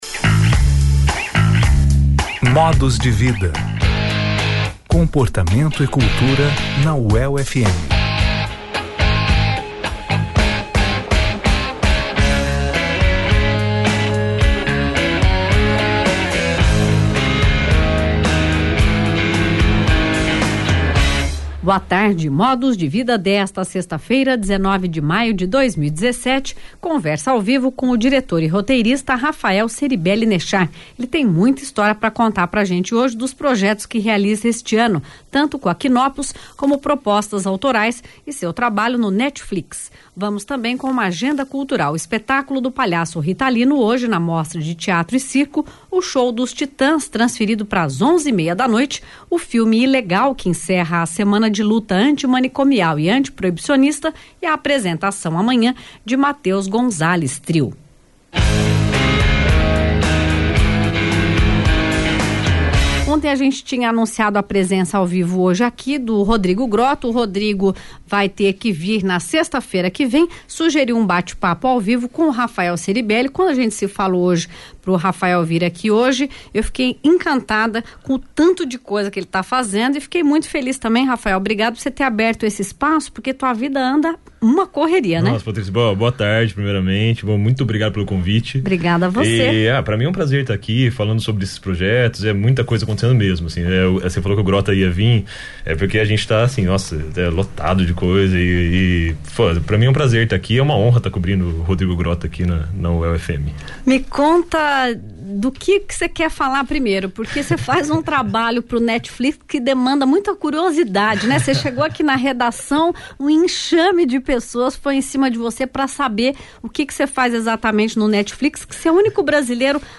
*Entrevista realizada no dia 19/05/2017, na Rádio UEL FM.